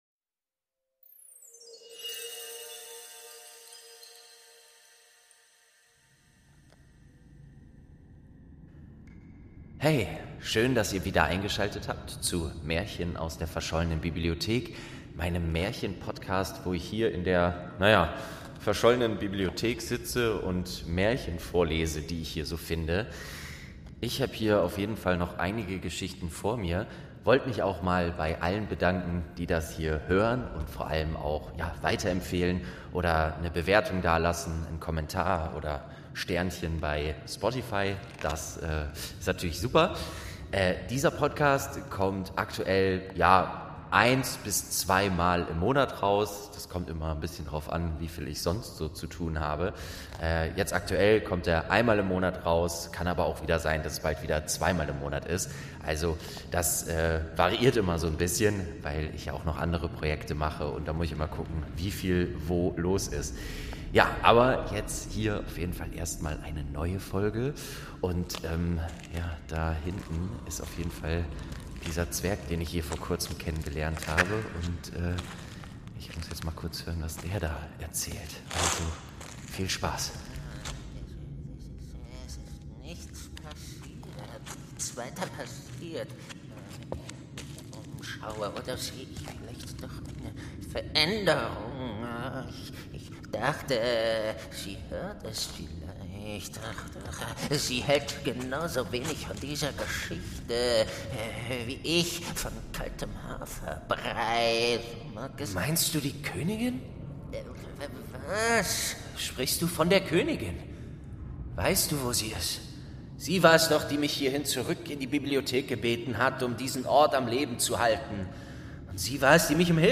7. Die Bremer Stadtmusikanten| Staffel 2 ~ Märchen aus der verschollenen Bibliothek - Ein Hörspiel Podcast